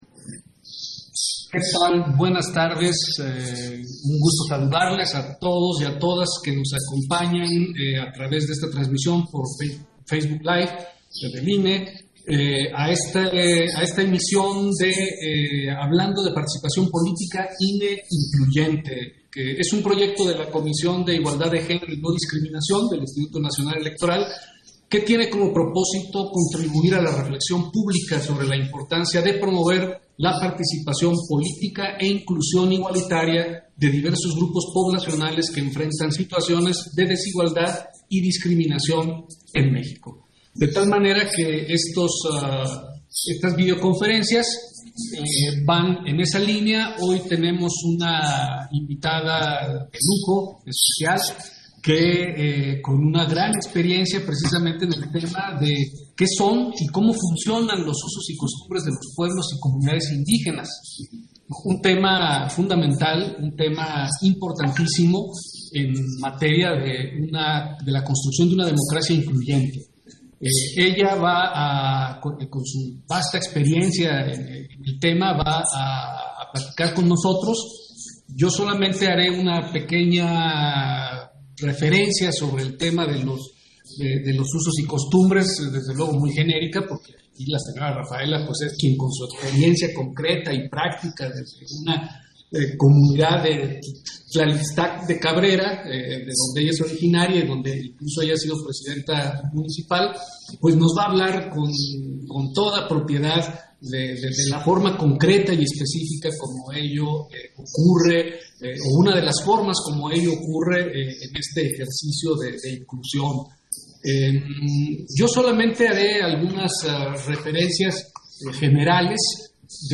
120321_AUDIO_INTERVENCIÓN-CONSEJERO-MARTÍN-FAZ-CONFERENCIA-VIRTUAL
Intervención de Martín Faz, en la conferencia ¿Qué son y cómo funcionan los usos y costumbres de los pueblos indígenas?